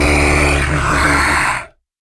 controller_idle_1.ogg